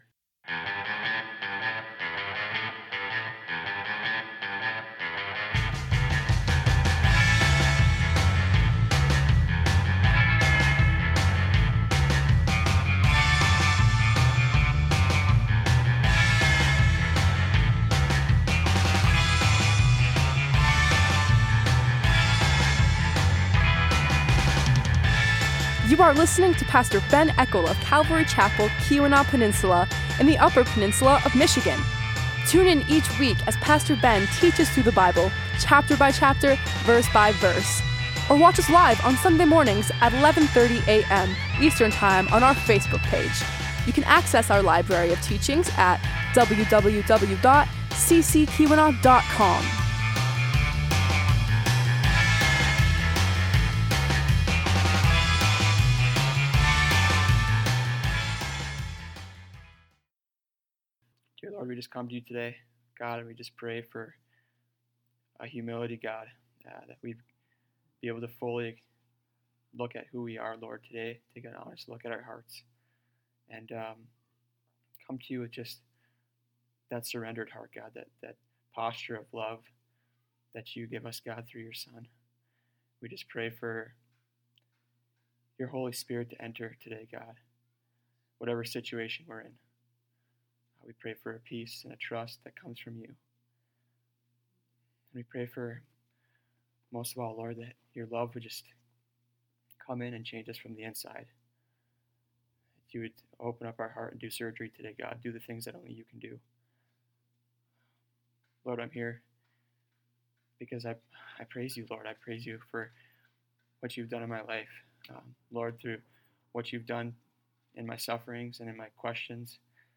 Series: Guest Speaker Service Type: Sunday Morning